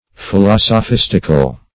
Search Result for " philosophistical" : The Collaborative International Dictionary of English v.0.48: Philosophistic \Phi*los`o*phis"tic\, Philosophistical \Phi*los`o*phis"tic*al\, a. Of or pertaining to the love or practice of sophistry.
philosophistical.mp3